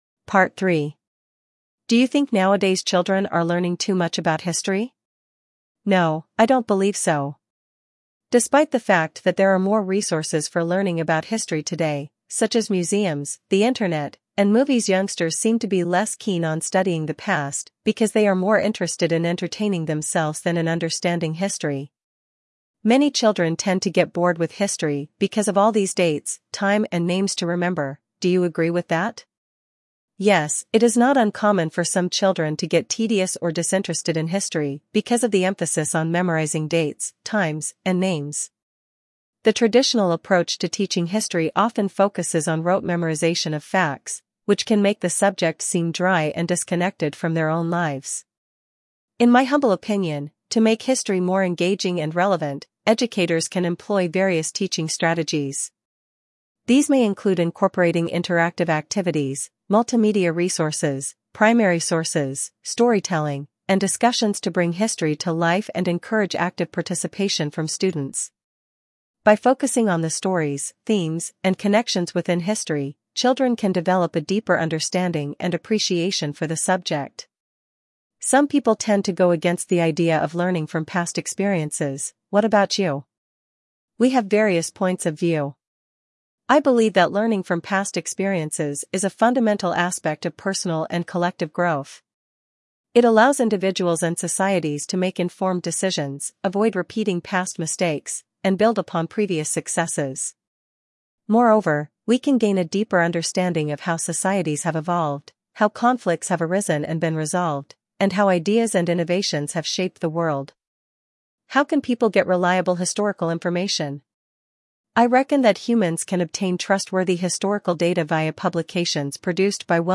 Jenny (English US)